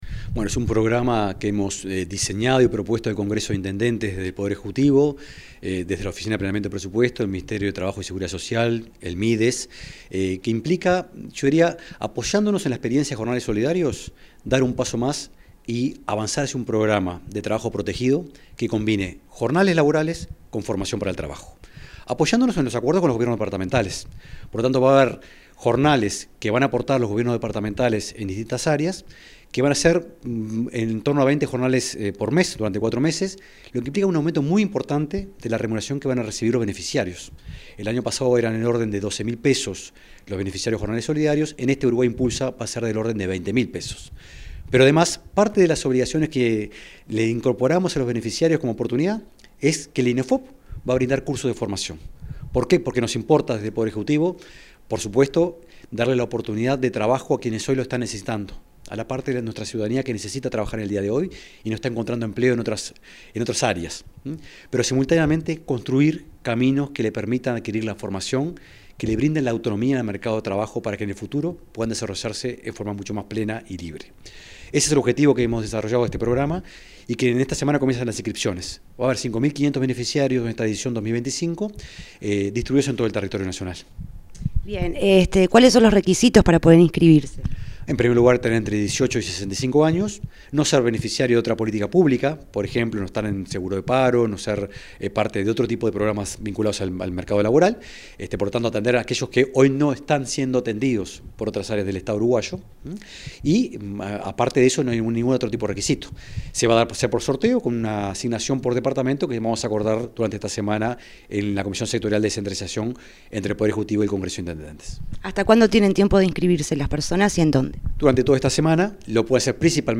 Declaraciones del director de la OPP, Rodrigo Arim